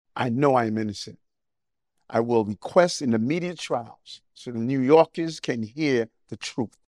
NEW YORK CITY MAYOR ERIC ADAMS IS FACING FEDERAL CHARGES AFTER BEING INDICTED WEDNESDAY NIGHT. IN A VIDEO STATEMENT RELEASED LAST NIGHT … THE MAYOR SAYS HE’S READY TO FACE THE INDICTMENT….